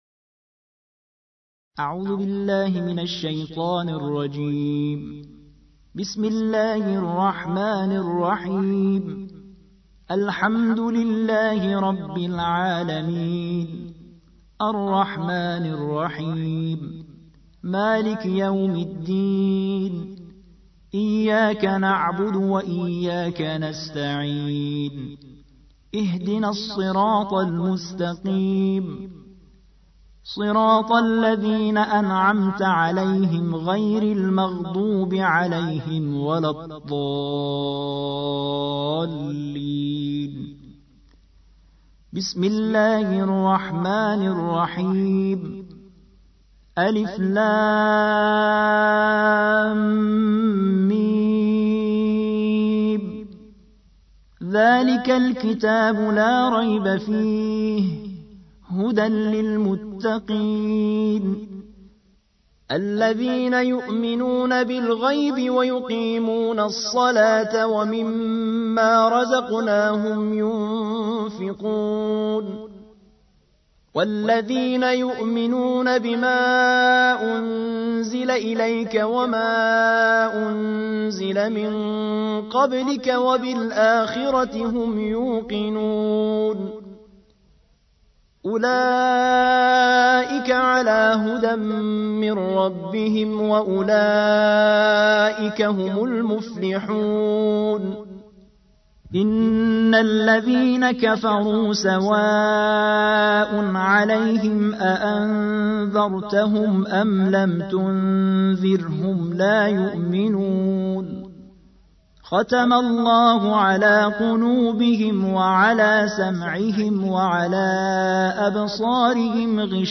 الجزء الأول / القارئ